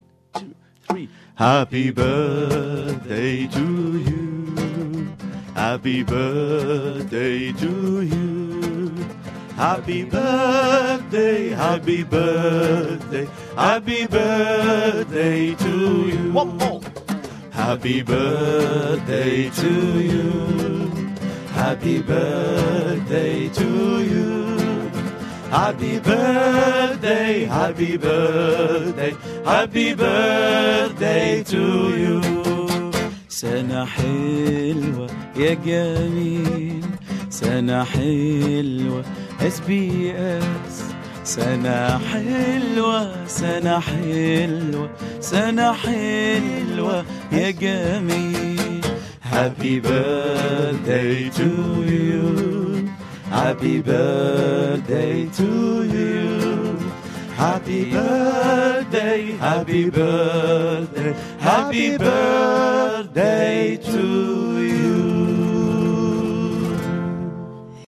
حلّ ثلاثي بلاد الرافدين ضيوفاً كراماً على استديوهات SBS عربي 24 في عيد ميلاد الاذاعة الاول. وقدّم لنا الثلاثي المميز أغنية خاصة بهذه المناسبة وأنشدوها بأصواتهم الرائعة